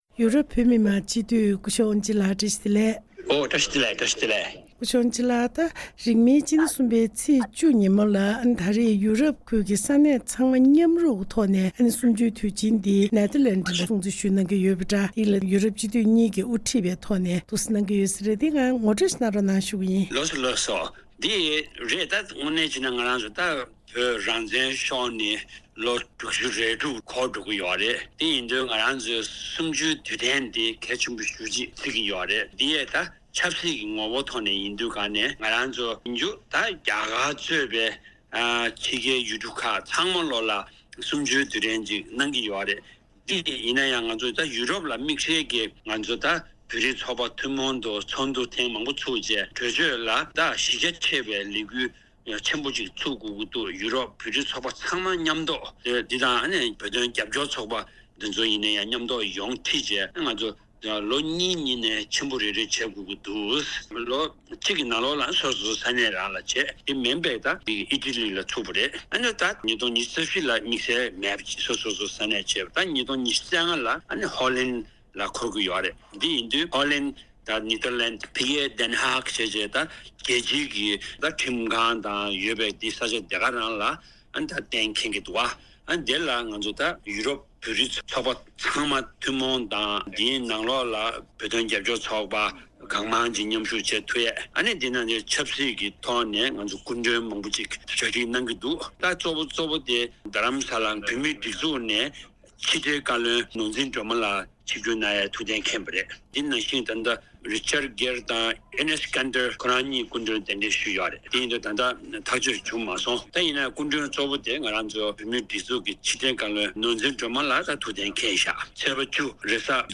གནས་འདྲིའི་ལེ་ཚན་ནང།